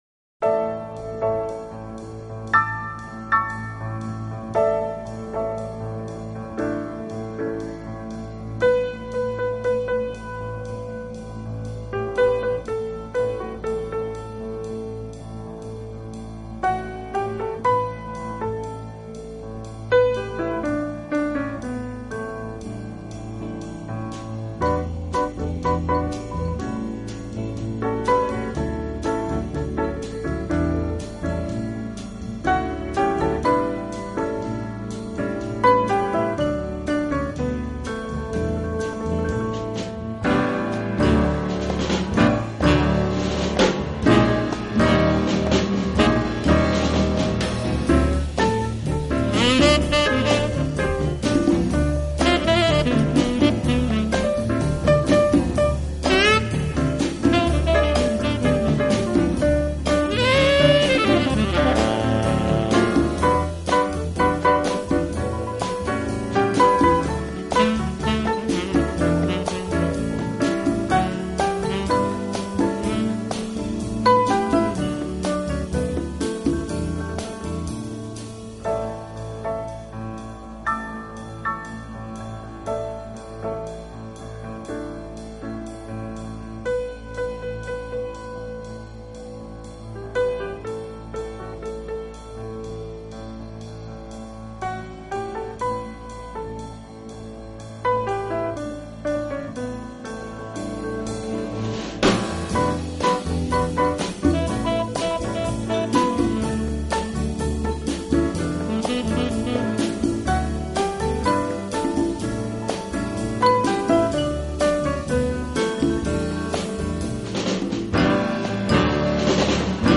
Genre: Jazz
Stereo